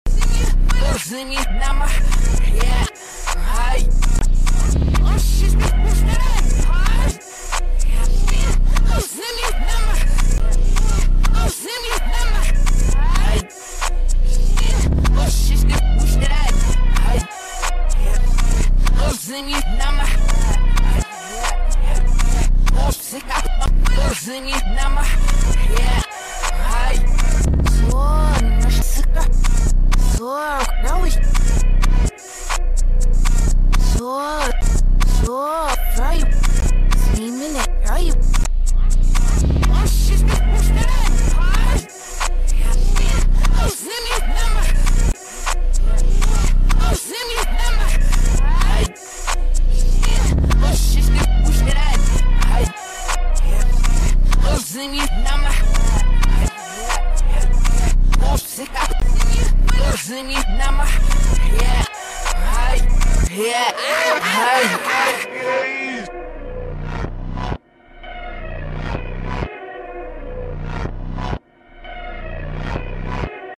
in reverse